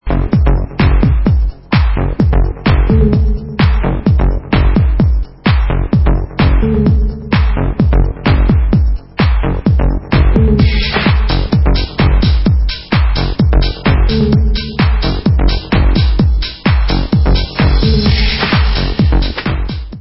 Vocal house